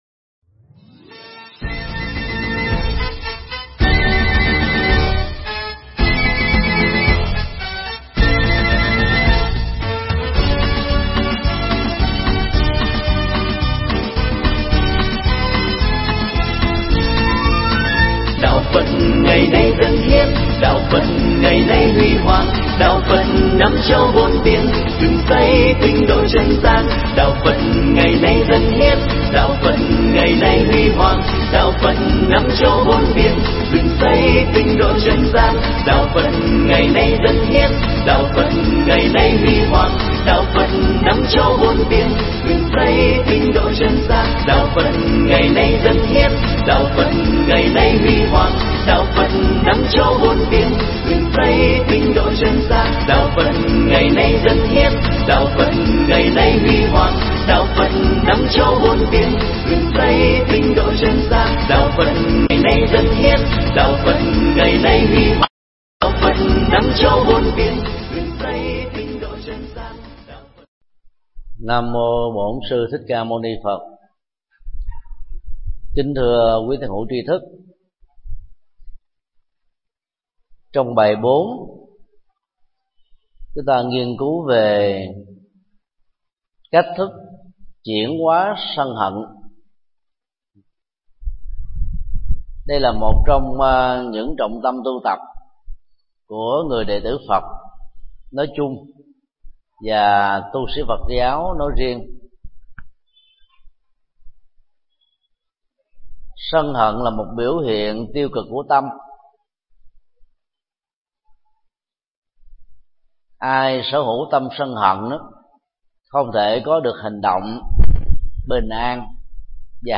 Nghe mp3 pháp thoại Kinh Di Giáo 04: Làm chủ sân hận
tại chùa Xá Lợi